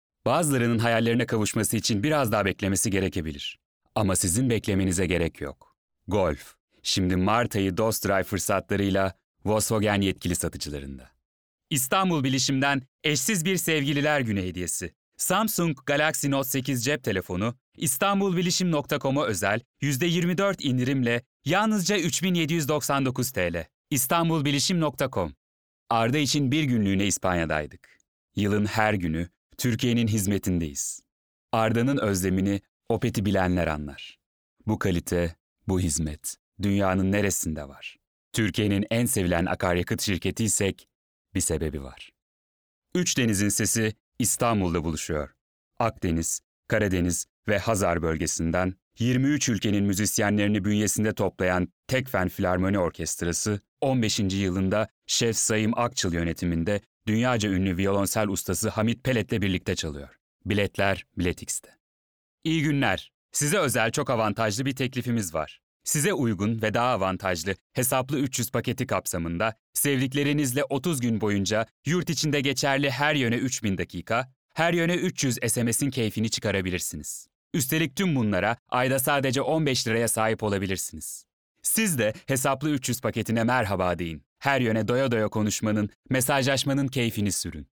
Genç & Orta Ses
Seslendirme + Kayıt + Miksaj